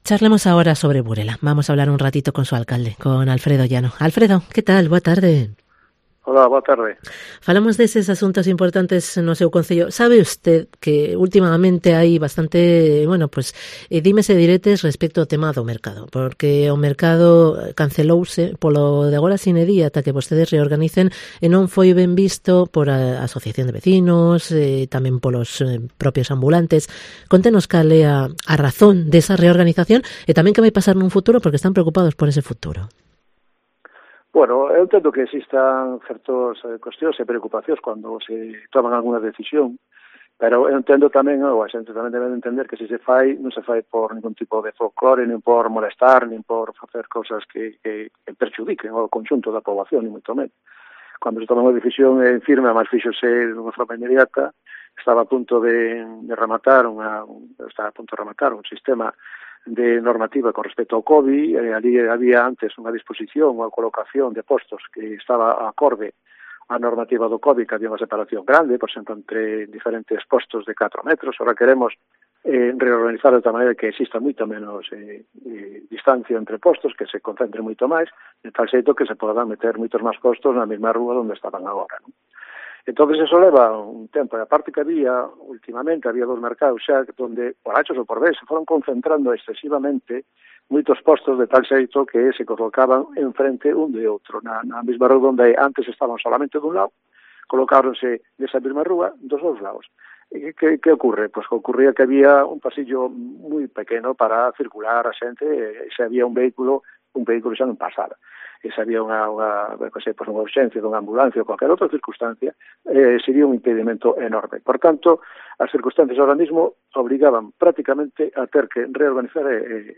Entrevista con Alfredo Llano, alcalde de Burela